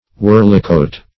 Search Result for " whirlicote" : The Collaborative International Dictionary of English v.0.48: Whirlicote \Whirl"i*cote\, n. An open car or chariot.